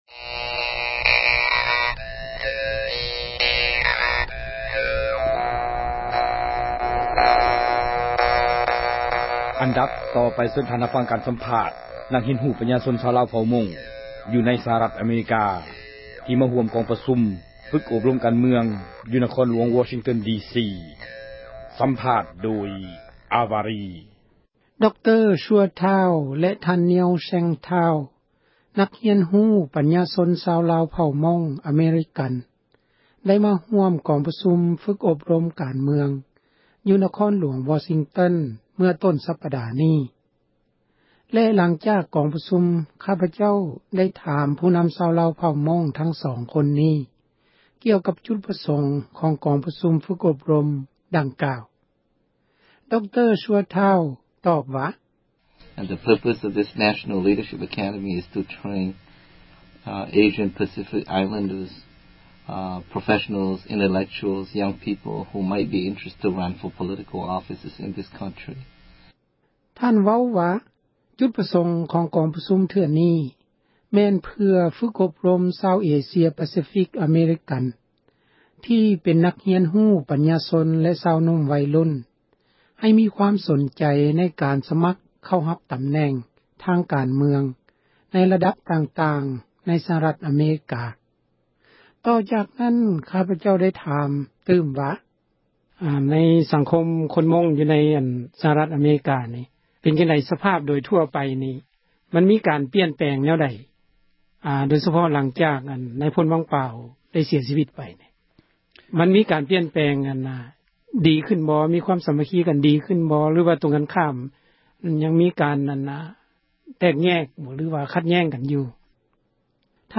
ສຳພາດນັກປັນຍາຊົນ ຊາວລາວເຜົ່າມົ້ງ